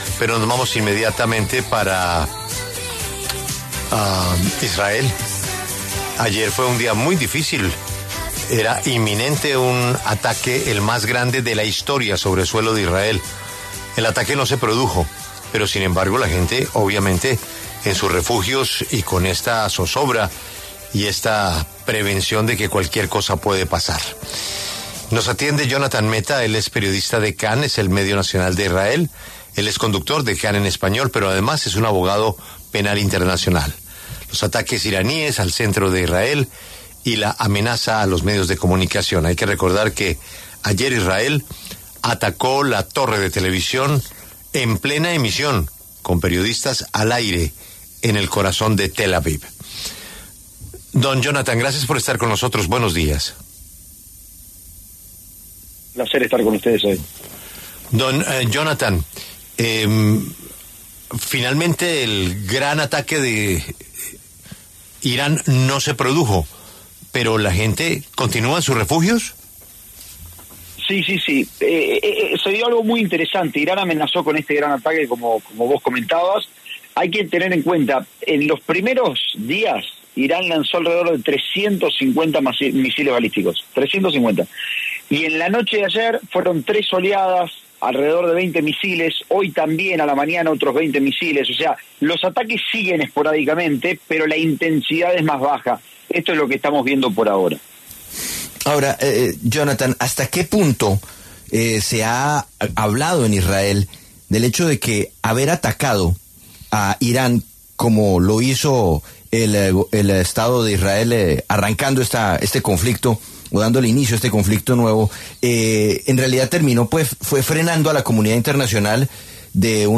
habló en La W sobre los más recientes ataques entre Irán e Israel y las consecuencias que estos han traído.